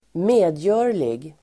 Ladda ner uttalet
Uttal: [²m'e:djö:r_lig]